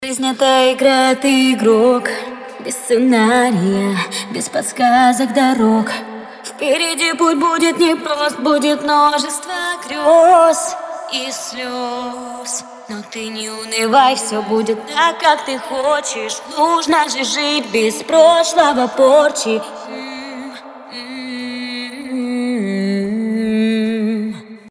Вот файл рзницы - уже понятно, что минус вычелся в ноль, остался только голос - вот тут и надо искать, что гадит.